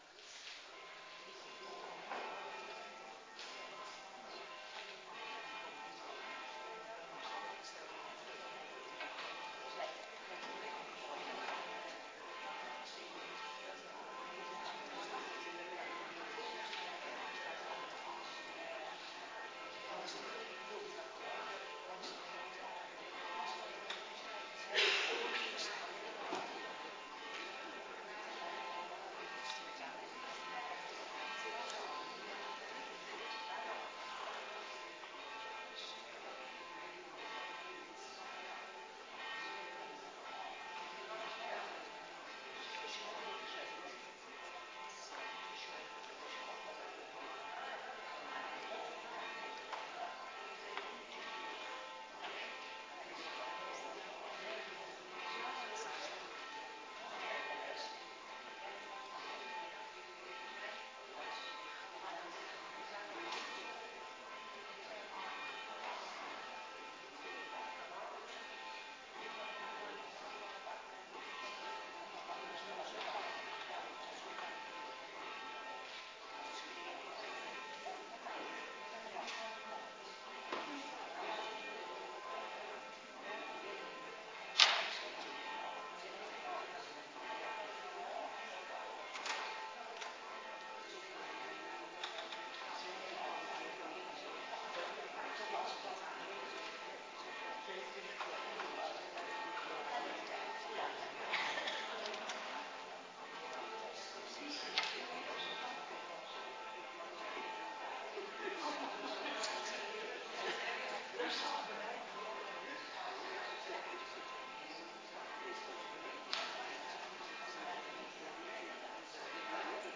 Adventkerk Zondag week 26